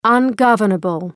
ungovernable.mp3